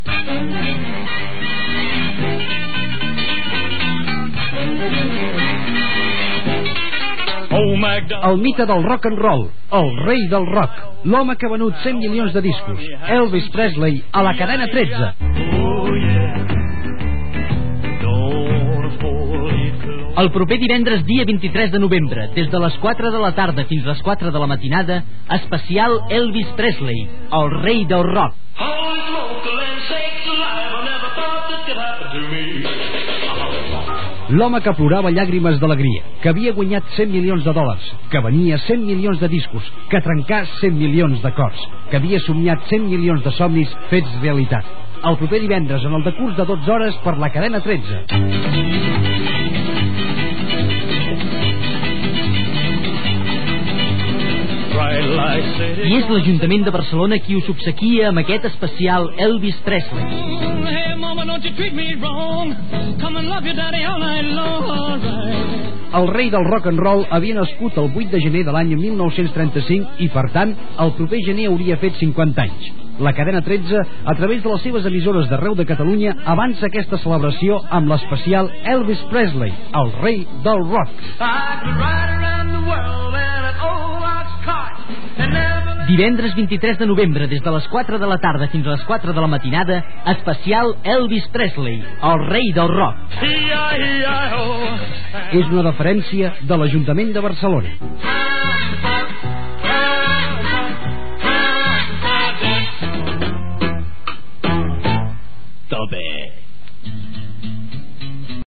Promoció del programa especial de 12 hores dedicat al cantant Elvis Presley
FM